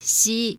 Phụ âm 「し」 và 「ち」 phát âm lần lượt là [ɕ]
Ja-Shi.oga.mp3